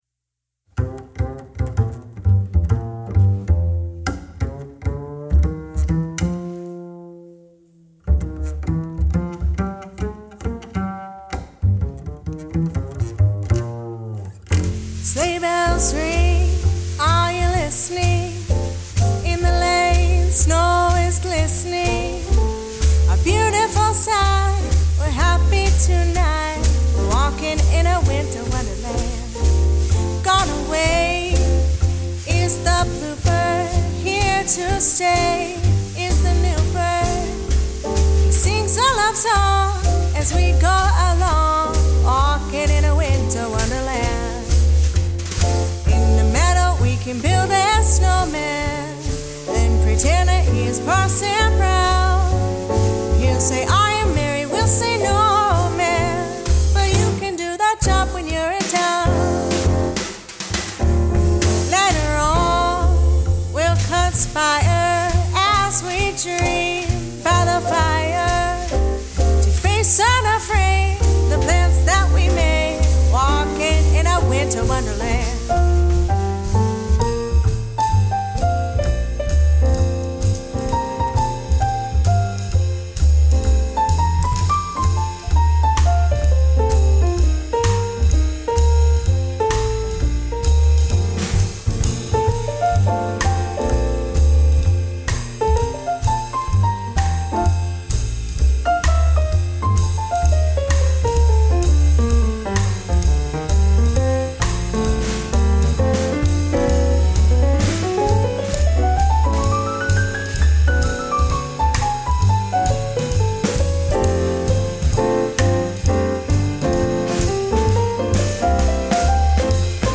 Christmas music to lift your spirit!